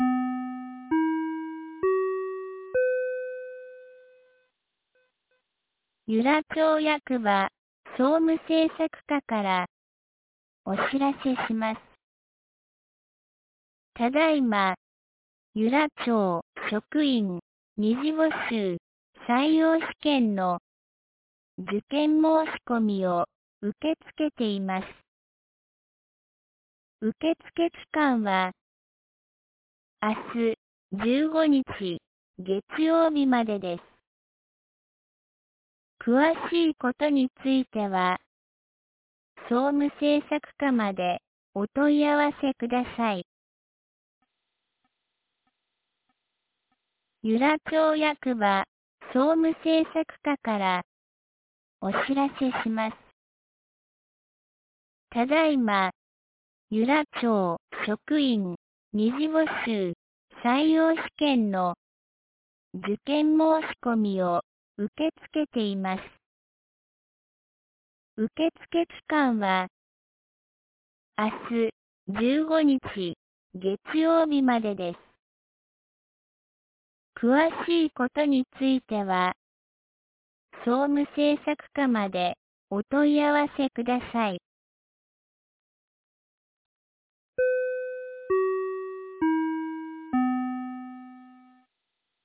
2025年12月14日 12時22分に、由良町から全地区へ放送がありました。